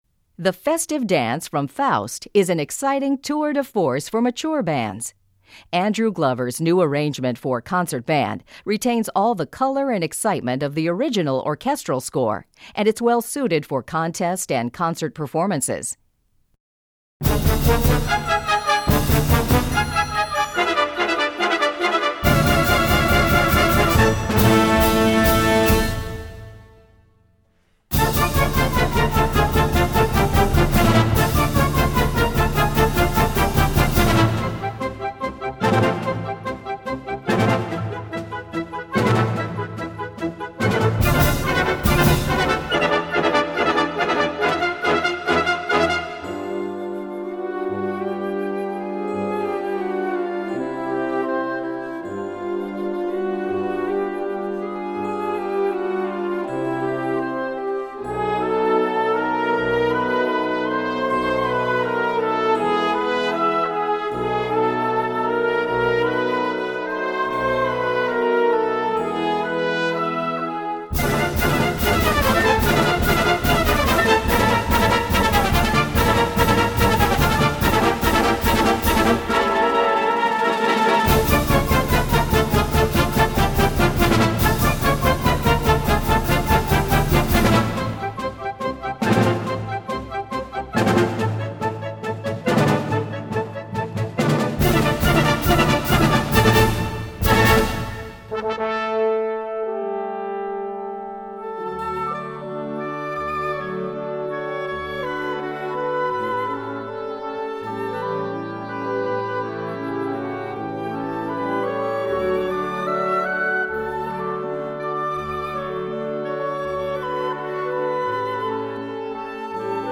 Besetzung: Blasorchester
Here's a thrilling tour-de-force showpiece for mature bands!